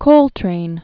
(kōltrān), John William 1926-1967.